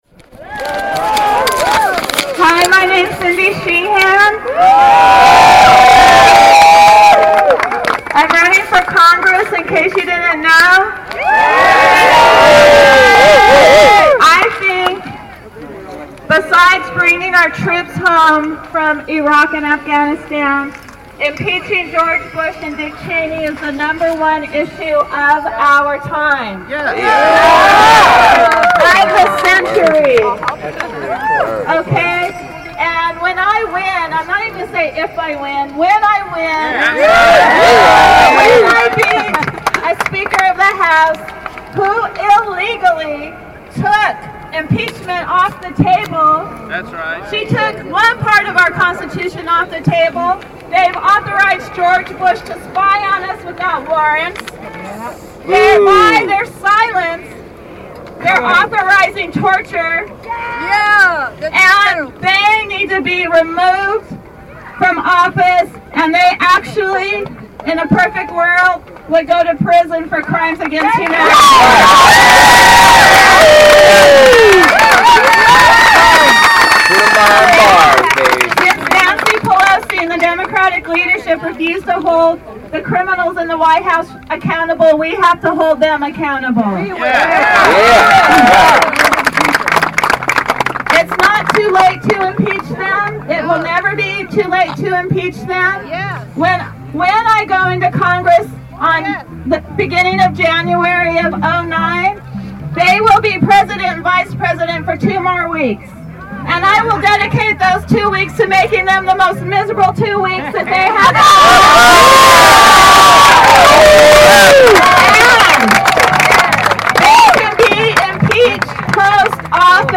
Cindy's voice was drowned out by unanimous cheers and applause.
§MP3 of Speeches
speeches_at_beach_impeach_4.mp3